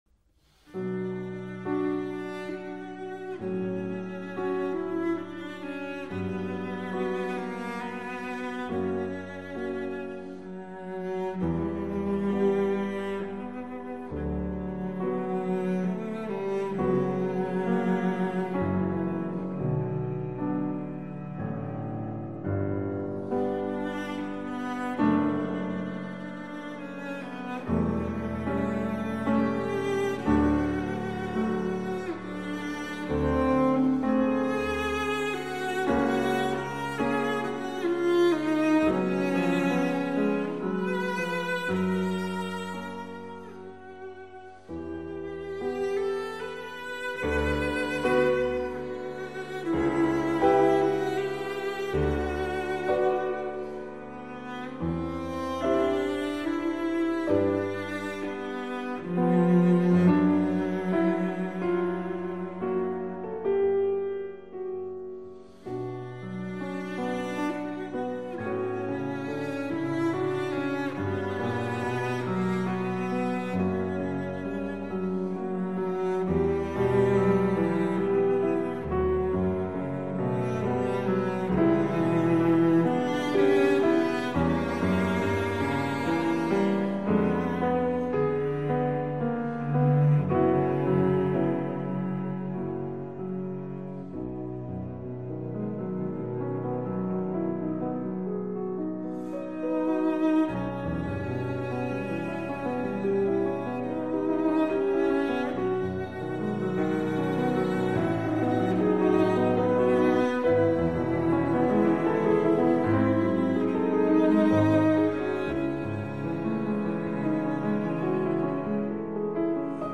Soundbite 2nd Movt
Sonata for Cello and Piano in D Major, Op.17